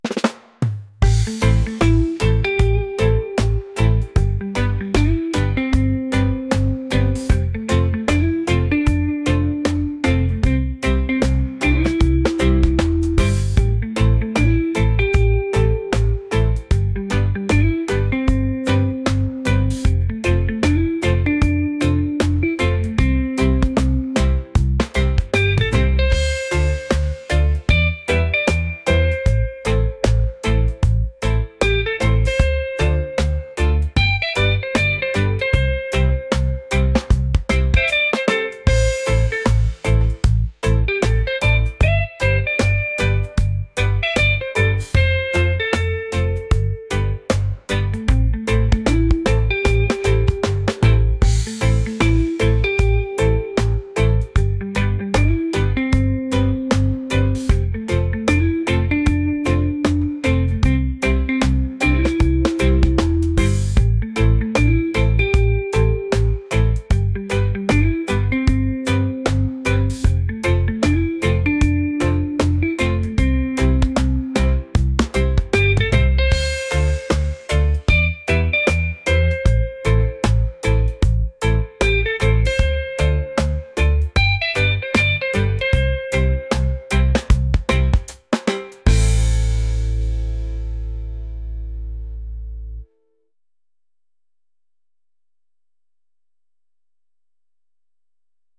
soulful | laid-back | reggae